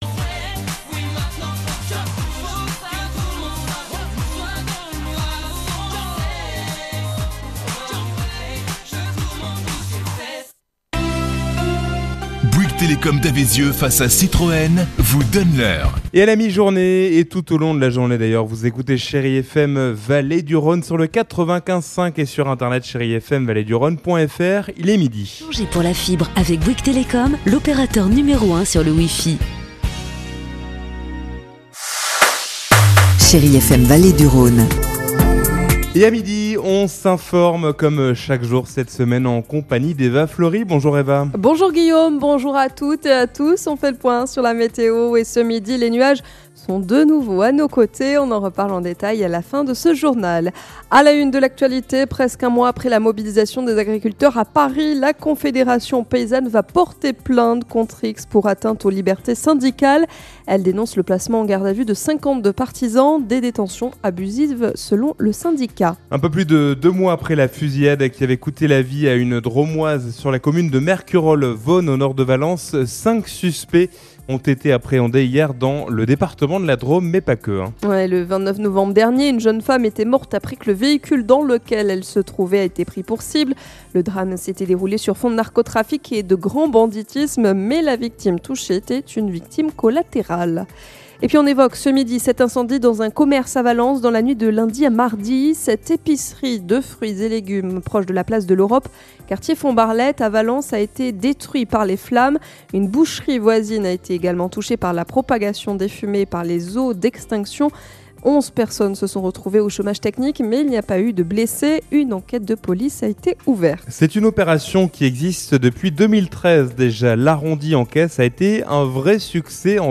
Mardi 10 février : Le journal de 12h